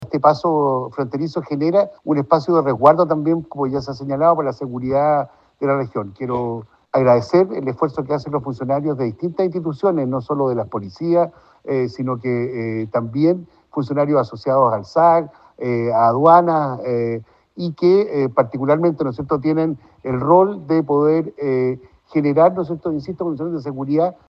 El delegado presidencial de La Araucanía, Eduardo Abdala, destacó el trabajo que a diario realizan funcionarios de las diferentes instituciones en el control fronterizo.